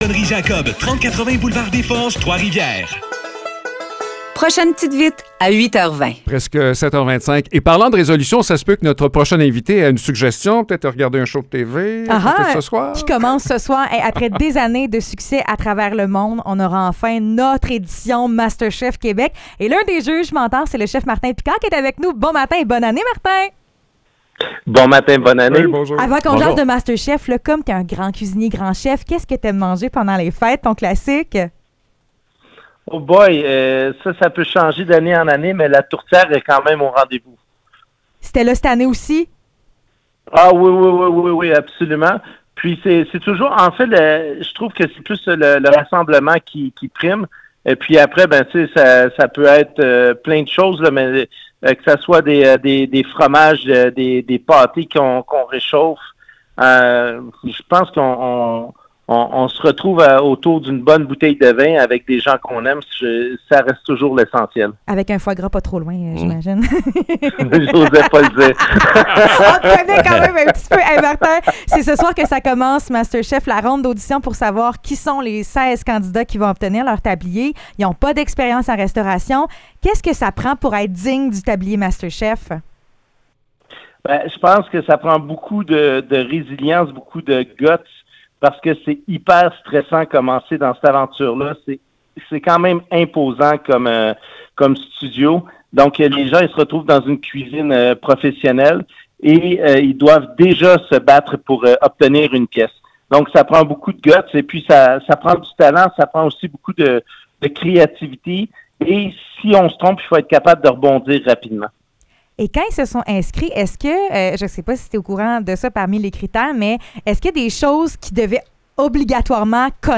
Entrevue avec Martin Picard pour Masterchef Québec